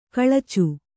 ♪ kaḷacu